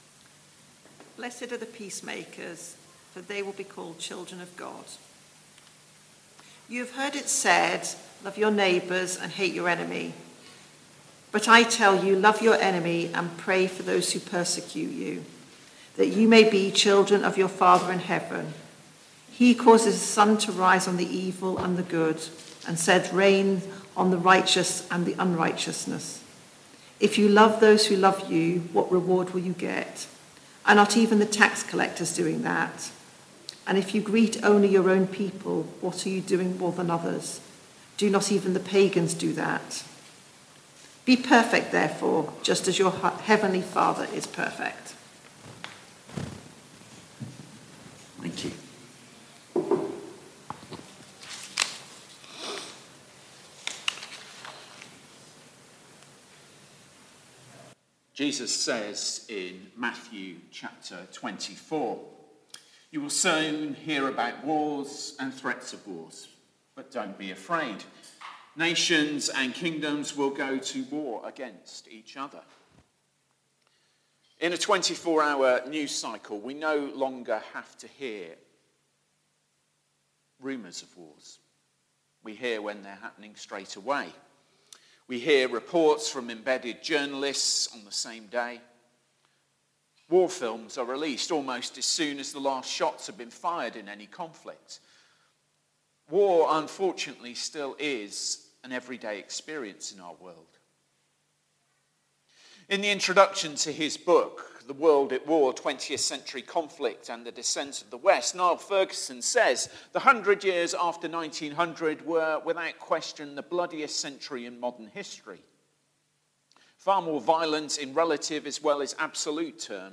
Woodstock Baptist Church Sermons